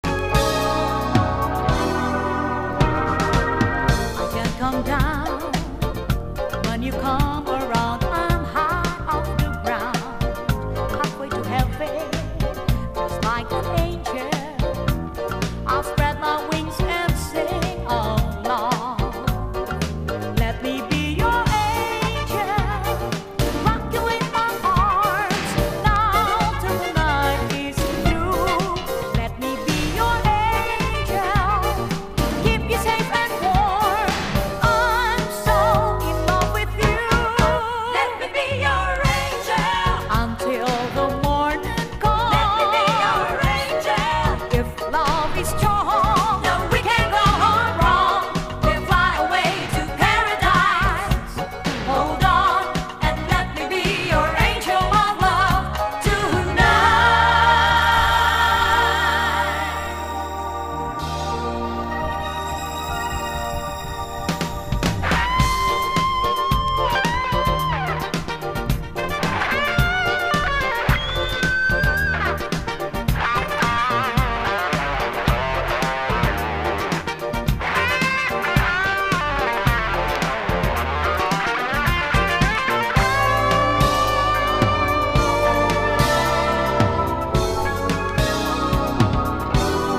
ダンサーチューン満載の83年作!!